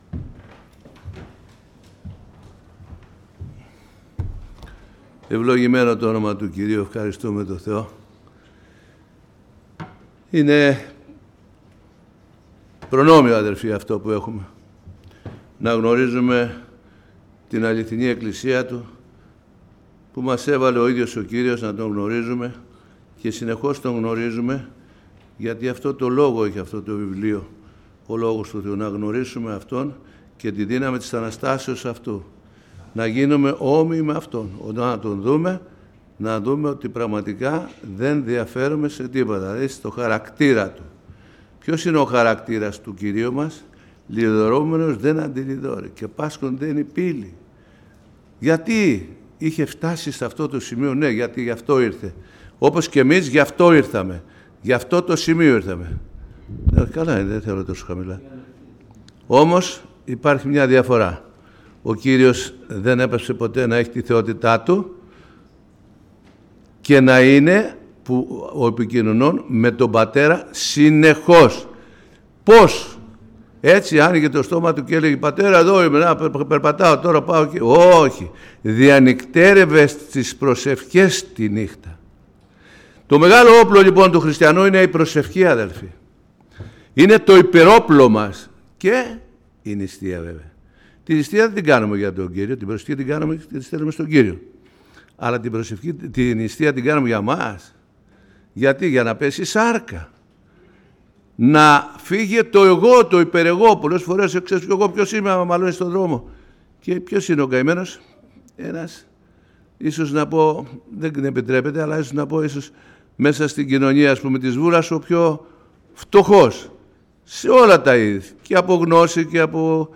Κήρυγμα Ευαγγελίου